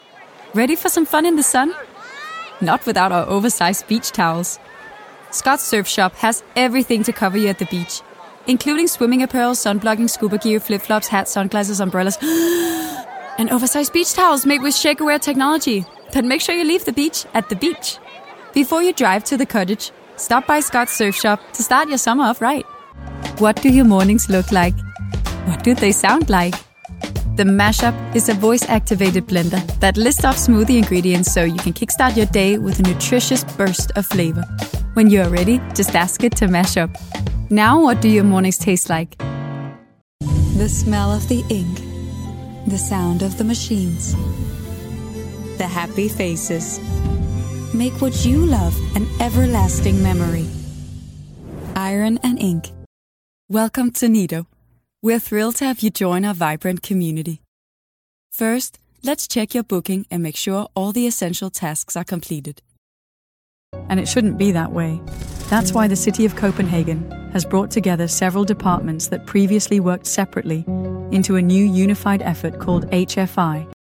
Danish, Scandinavian, Female, Home Studio, 20s-30s